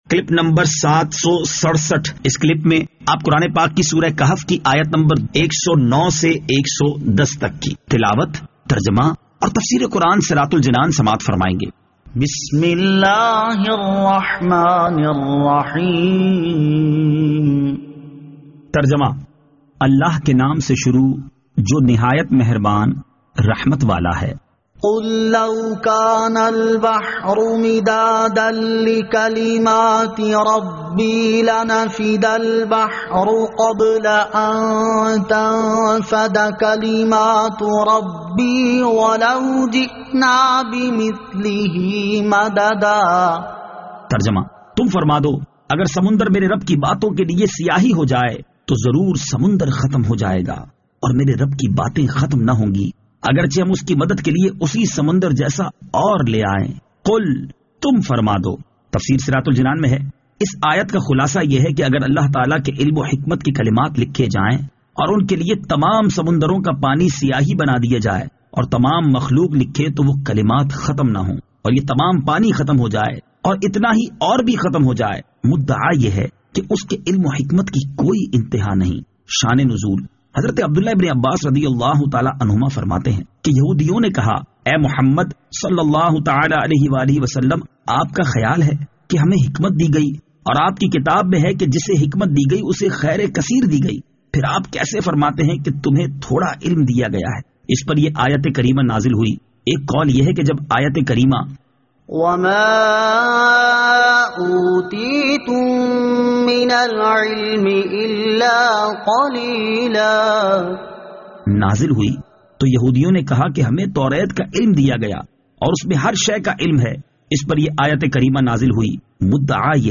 Surah Al-Kahf Ayat 109 To 110 Tilawat , Tarjama , Tafseer